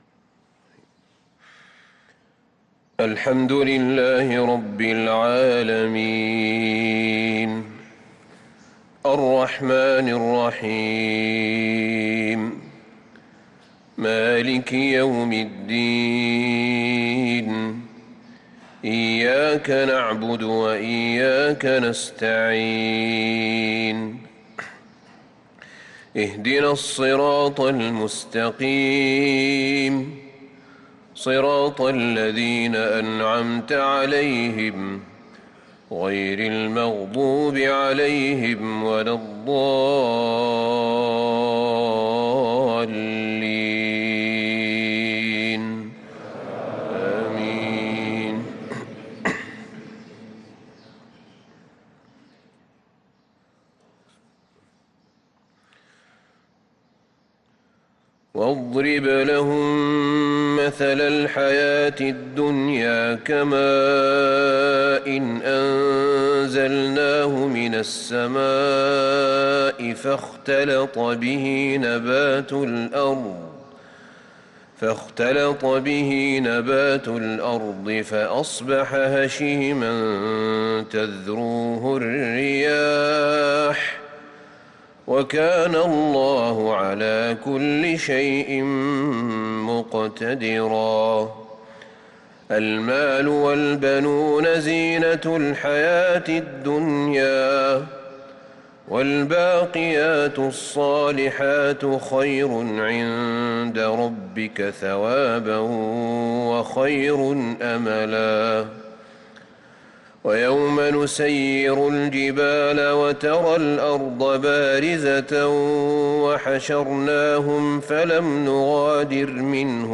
صلاة الفجر للقارئ أحمد بن طالب حميد 7 رجب 1445 هـ